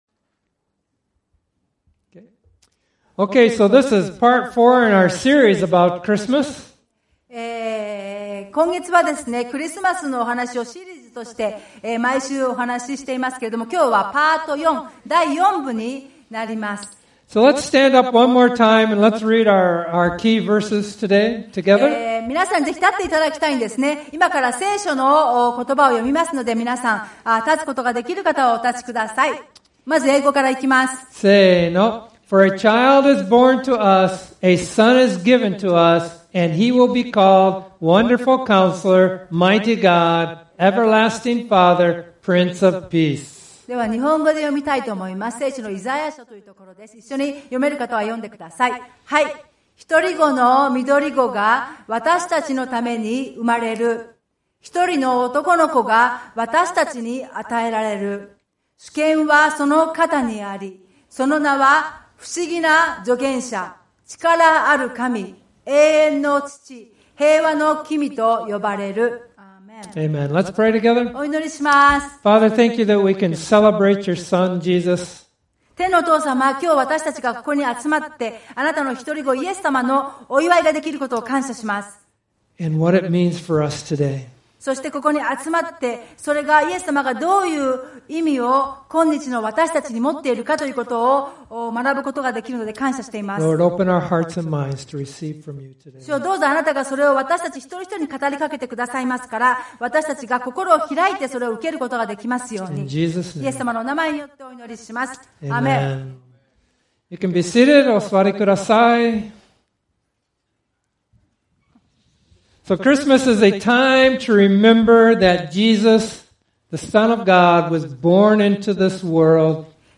Christmas Service: Jesus; The Hope of the World | Every Nation Church Izu